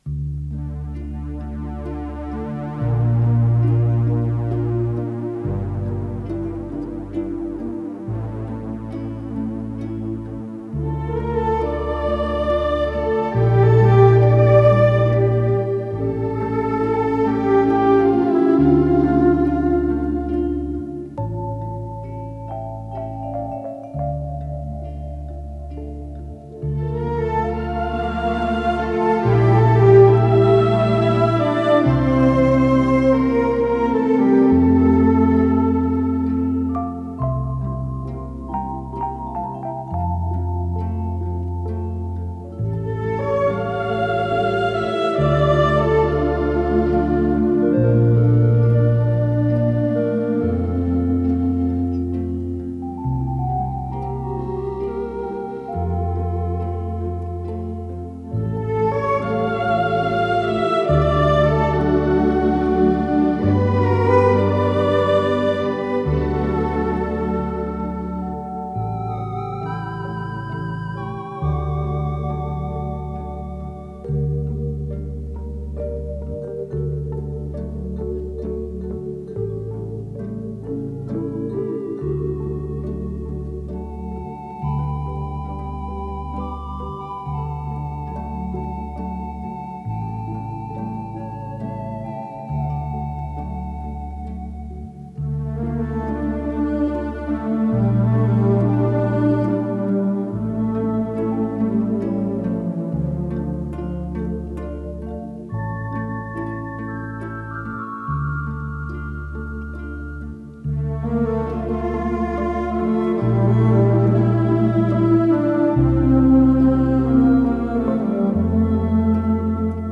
с профессиональной магнитной ленты
ИсполнителиОркестр электромузыкальных инструментов Всесоюзного радио и Центрального телевидения
Скорость ленты38 см/с
ВариантДубль моно